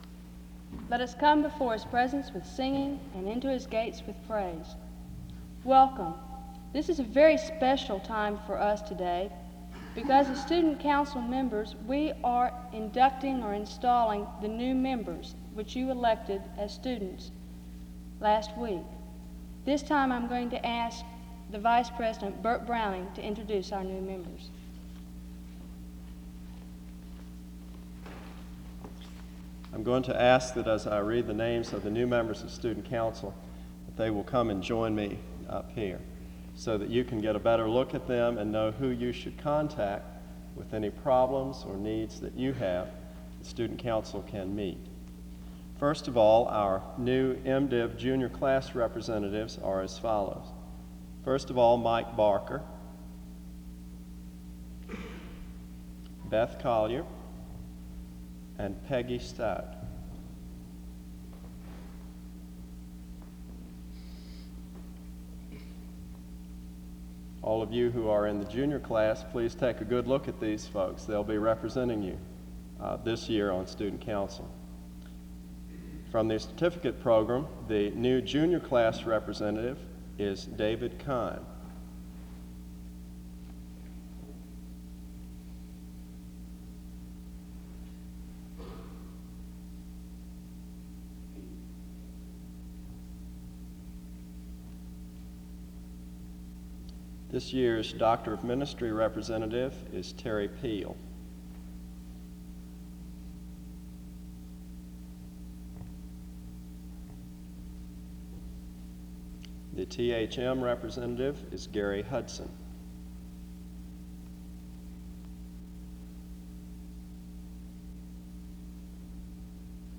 Download .mp3 Description The service starts with a welcome to the service from 0:00-0:25 The new council members are introduced from 0:25-3:00.
Music plays from 8:33-13:39.
She delivers a message on the topic of community. The service closes with prayer and music from 27:37-28:43. This service was organized by the Student Coordinating Council.